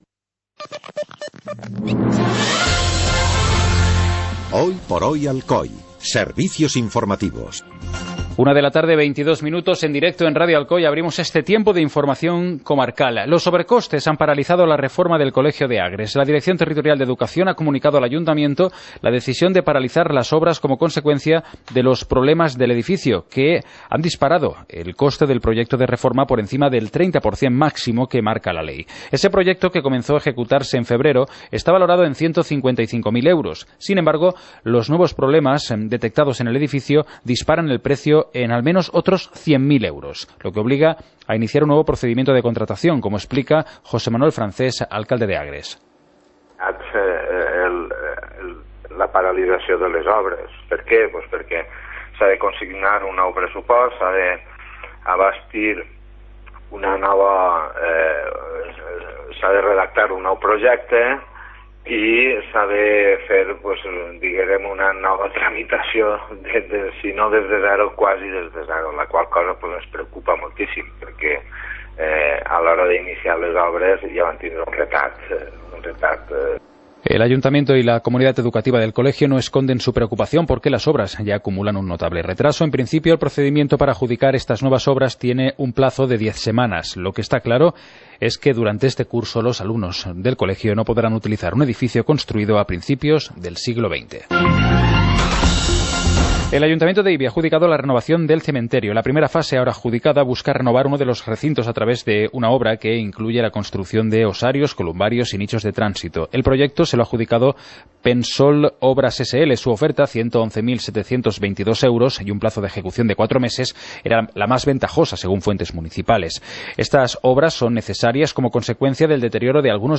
Informativo comarcal - jueves, 17 de abril de 2014